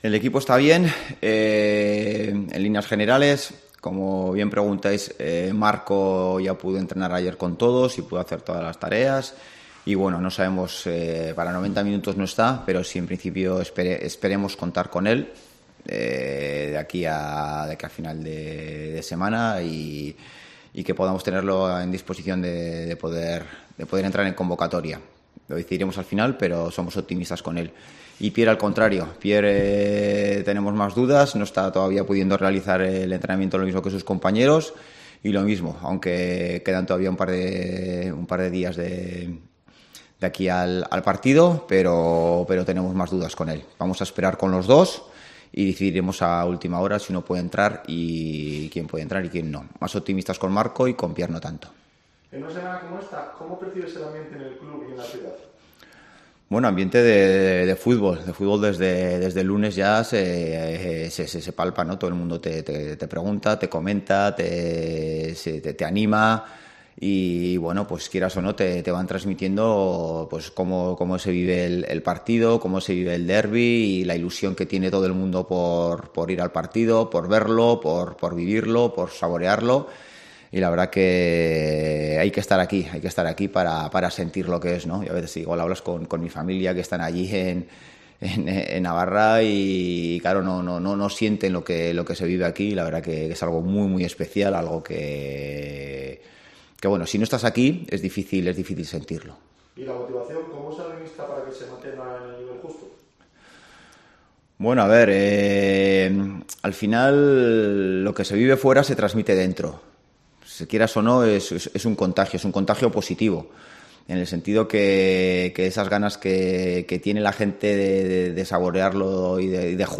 Rueda de prensa Ziganda (previa derbi)